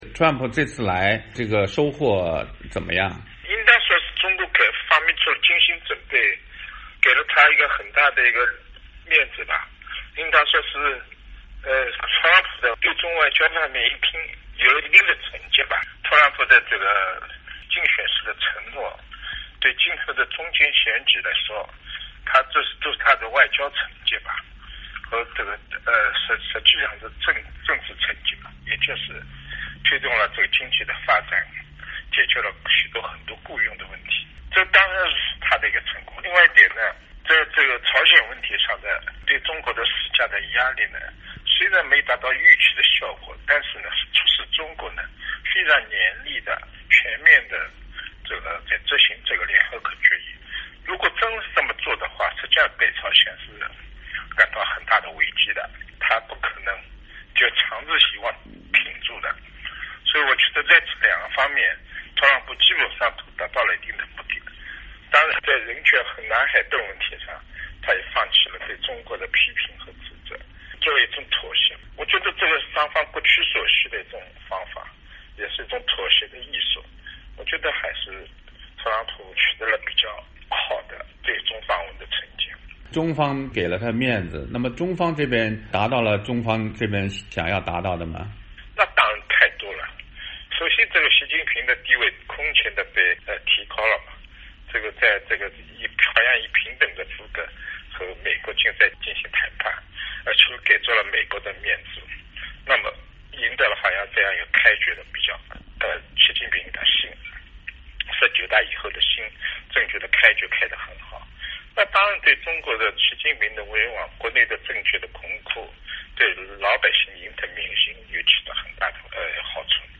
美国总统川普在他访华期间同中国国家主席习近平热络互动，双方在会谈中就美方高度关切的朝核危机、贸易失衡等议题进行了深入讨论，而对于敏感的南中国海岛礁和人权问题似乎着墨不多。美国之音对一些研究美中关系和东亚问题的专家进行了电话专访，分析川普这次北京之行的得失利弊和美中关系今后的走向及其影响。